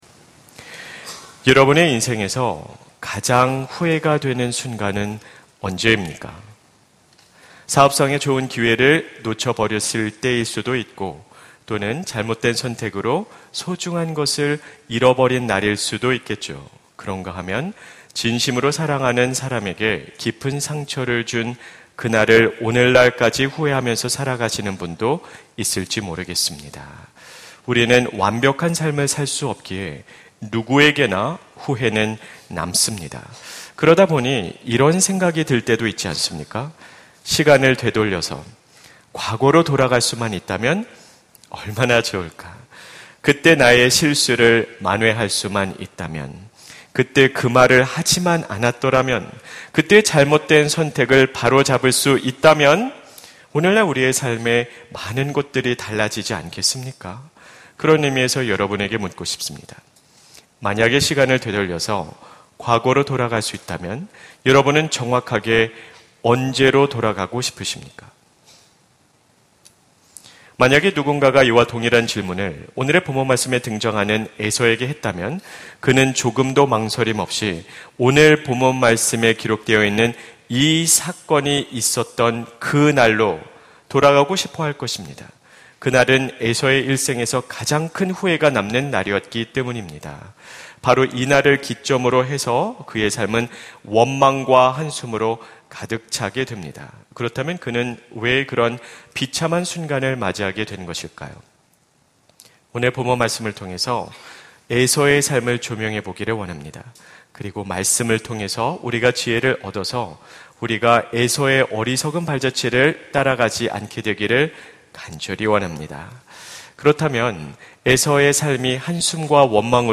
설교 : 주일예배